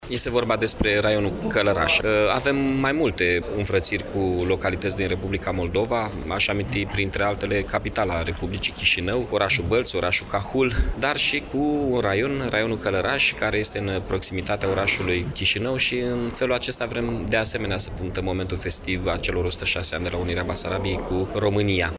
La Iași, a fost semnat, în ședința Consiliului Local de astăzi, protocolul de înfrățire între municipiul Iași și Raionul Călărași, din Republica Moldova.